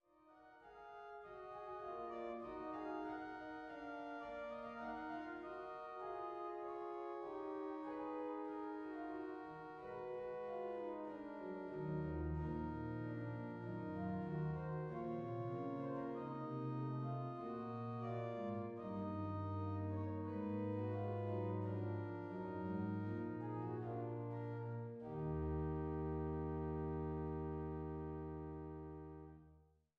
Hildebrandt-Orgel in Langhennersdorf